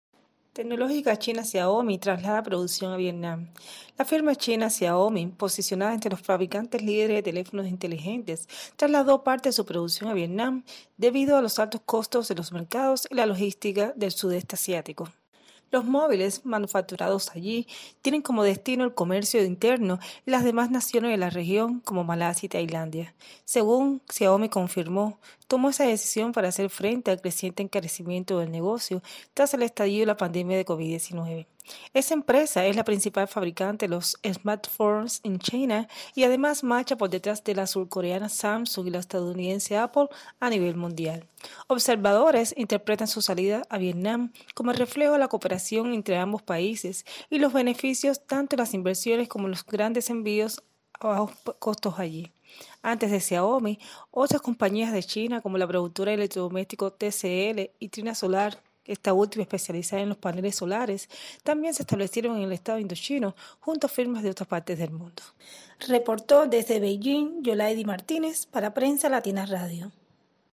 desde Beijing